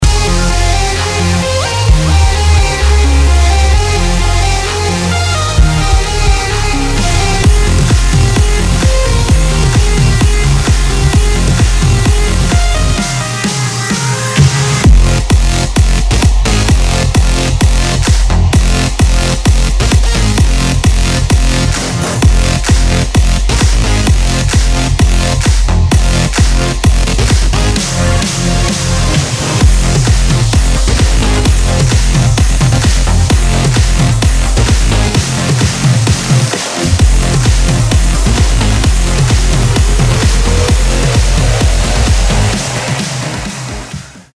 Клубные
Клубная бомба просто разносящая колонки!
Метки: электронные,